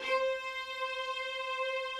strings_060.wav